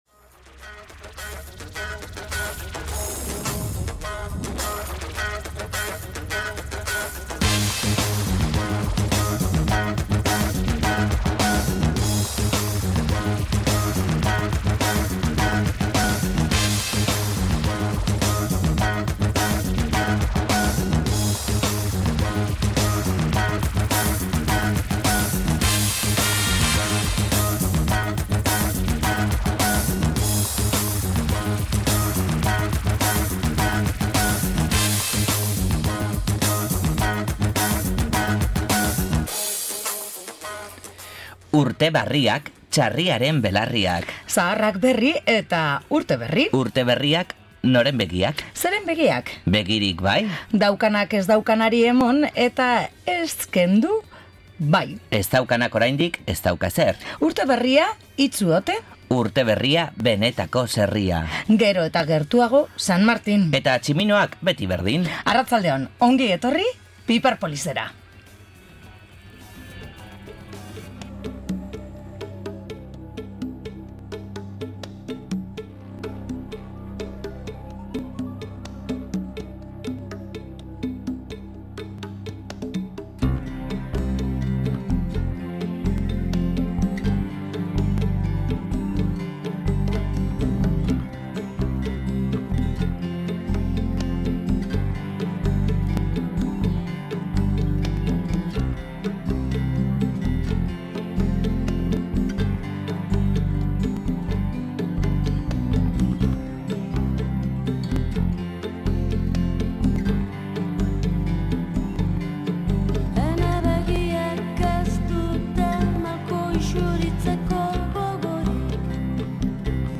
Batetik, bikote akustiko bizkaitarra den Hutsa taldekideekin hitz egin dugu.